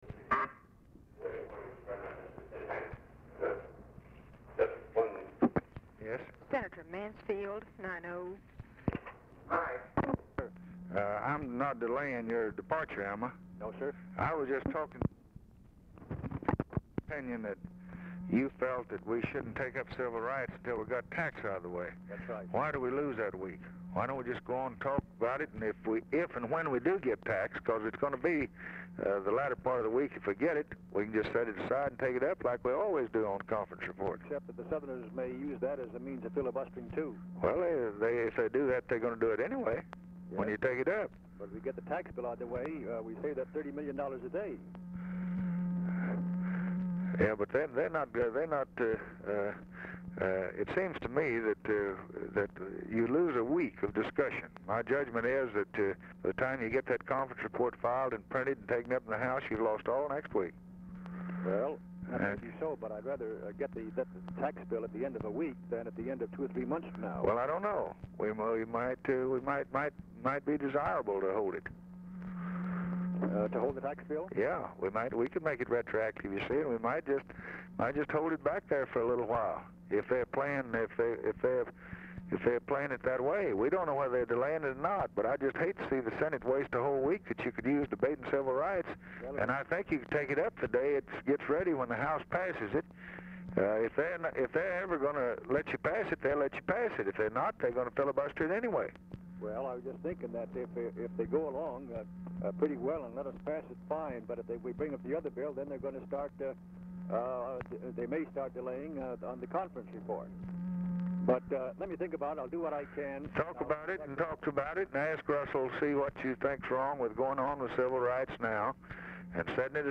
White House Telephone Recordings and Transcripts
Oval Office or unknown location
Telephone conversation
Dictation belt